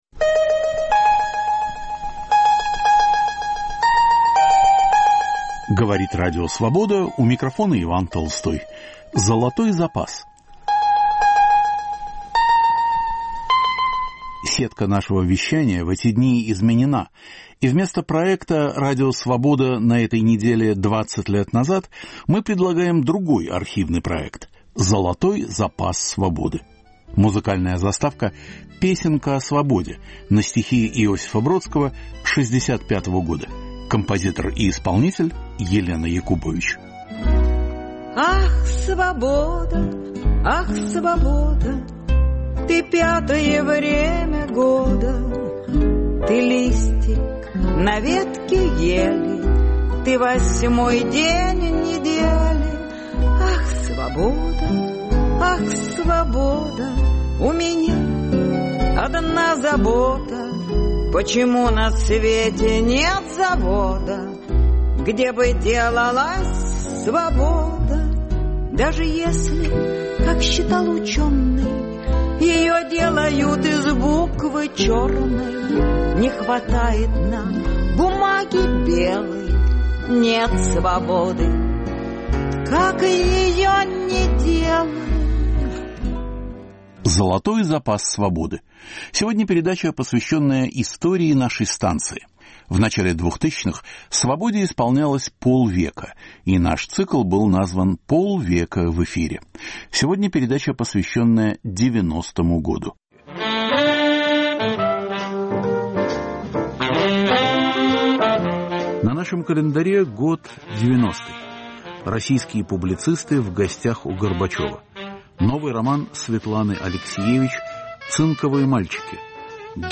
К 50-летию Радио Свобода. 1990, архивные передачи: роман Светланы Алексиевич "Цинковые мальчики". Гибель Виктора Цоя. Юлий Ким, Булат Окуджава, Юнна Мориц выступают перед микрофоном Свободы.